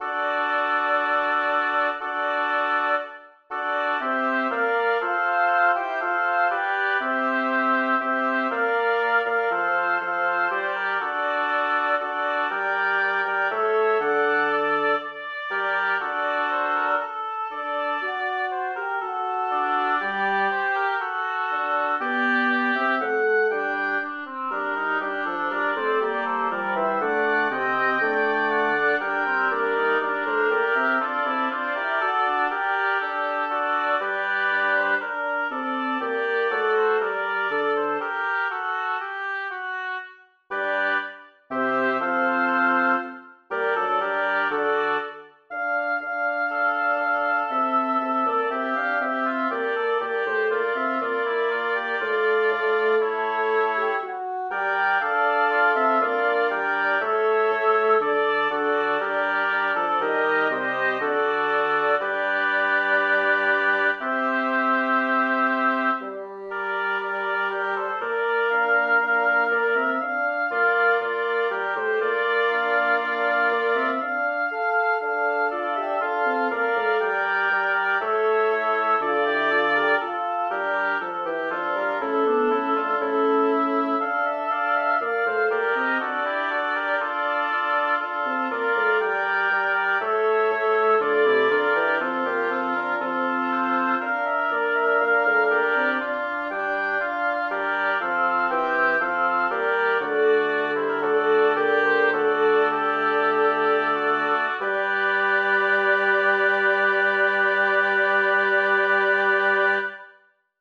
Title: Dice la mia bellissima Licori Composer: Orazio Vecchi Lyricist: Giovanni Battista Guarini Number of voices: 5vv Voicing: SSATB Genre: Secular, Madrigal
Language: Italian Instruments: A cappella